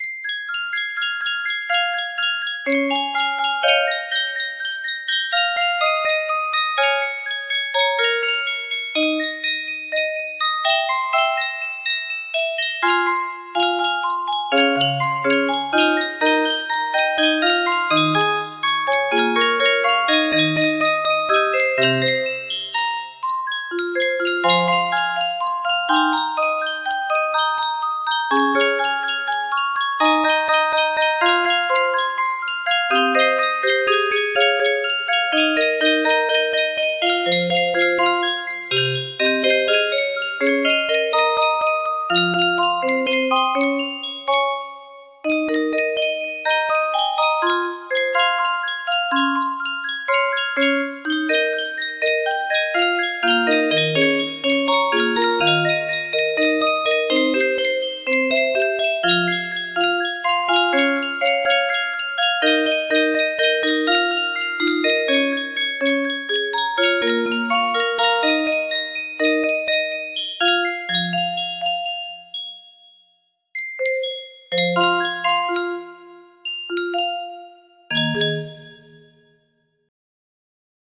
Sail To The Moon music box melody
Full range 60